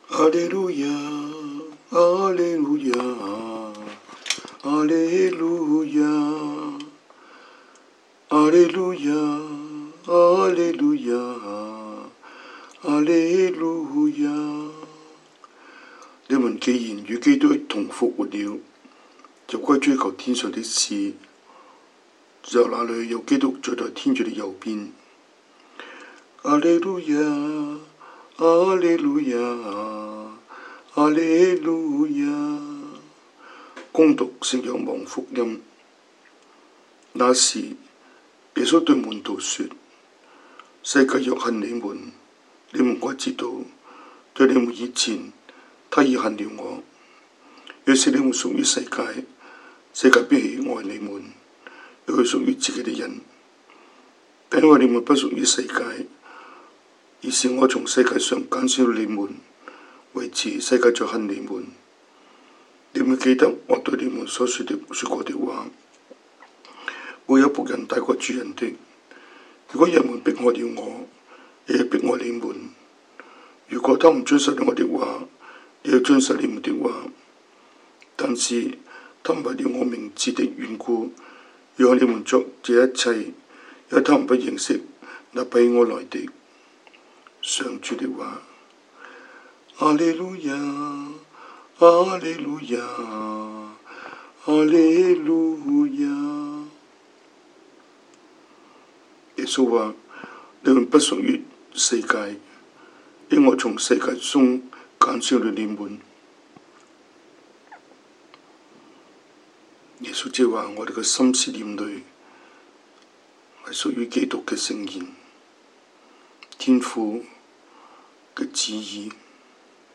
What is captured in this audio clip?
Cantonese Homily, Eng Homily